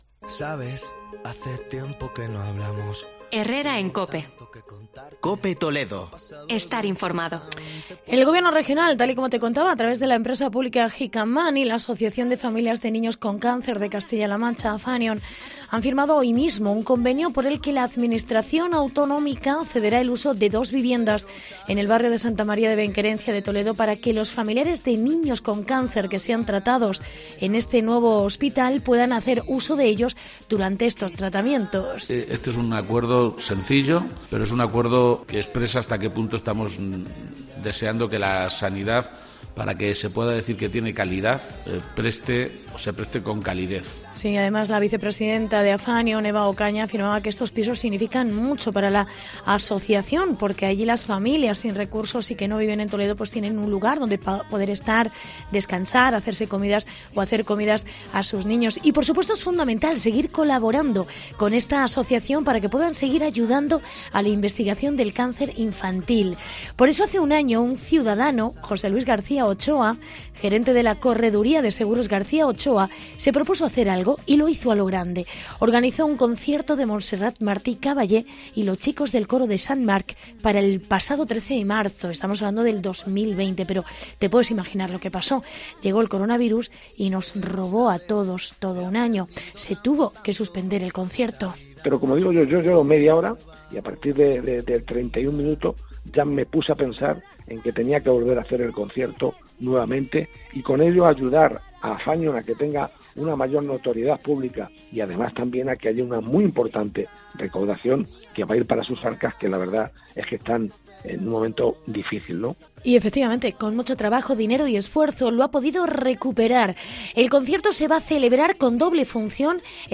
Reportaje concierto Montserrat Martí Caballé a beneficio de Afanion